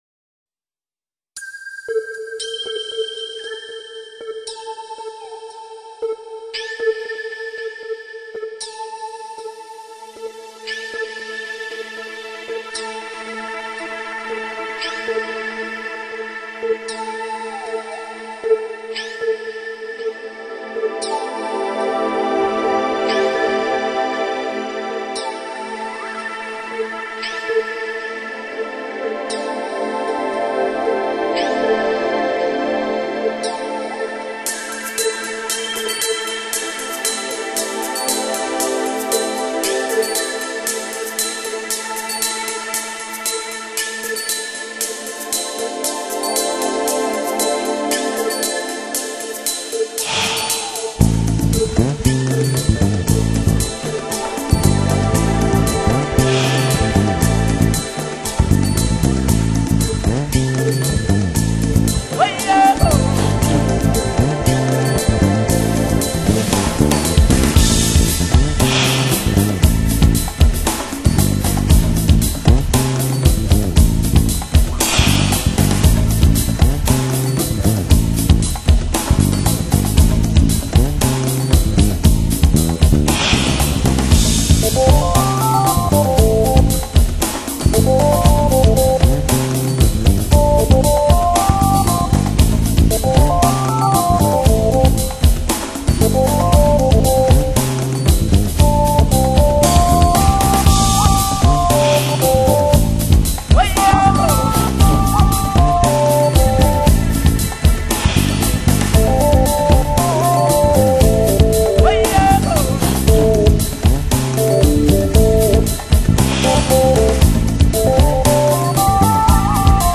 原住民手下的鼓在太阳的炙烤下自然诞生了火焰般跳跃的节奏。
它所独具的活力和强烈的感染力。